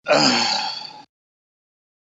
Man Sigh 2
yt_ZwUSYtz0PQg_man_sigh_2.mp3